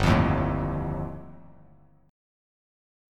G7sus2#5 chord